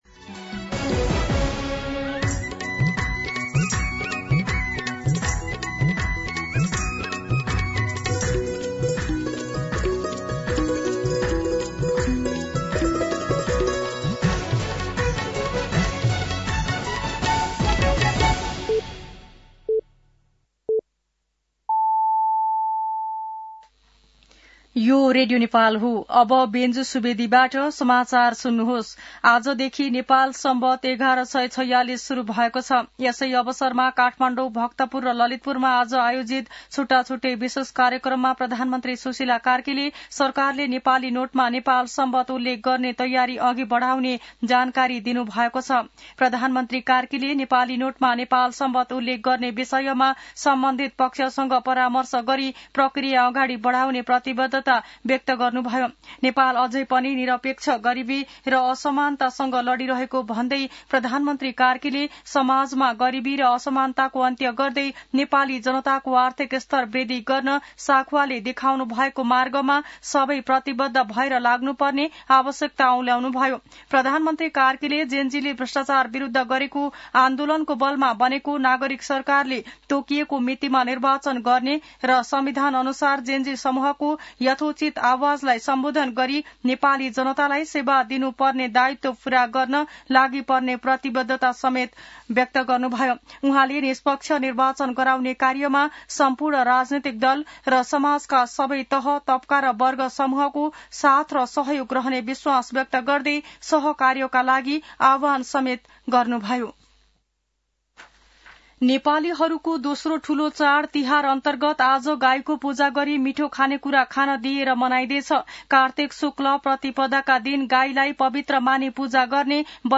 दिउँसो १ बजेको नेपाली समाचार : ५ कार्तिक , २०८२
1pm-News-05.mp3